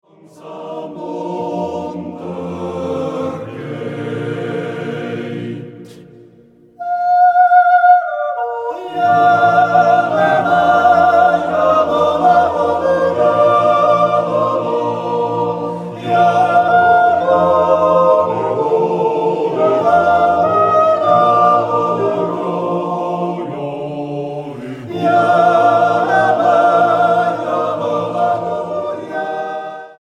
Description:Folk music; yodel; yodelling song
Instrumentation:Yodelling choir